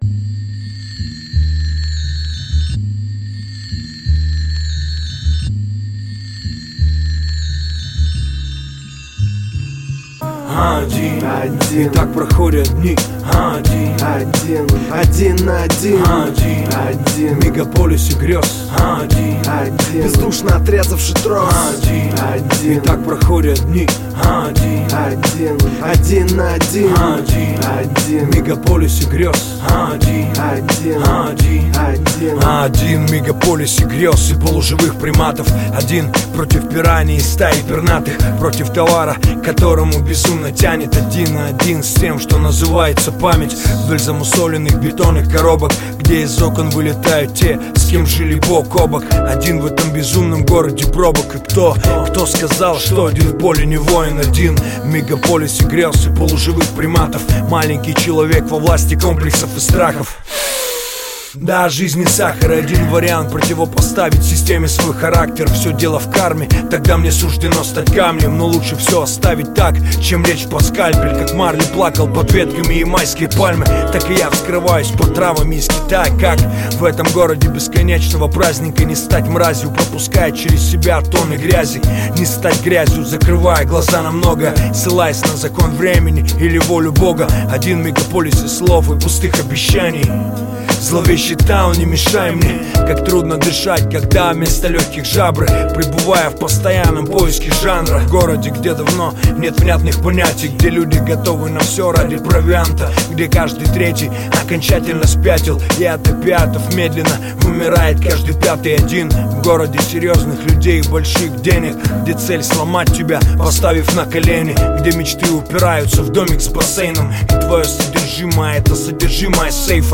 Русский рэп
Жанр: Русский рэп / Хип-хоп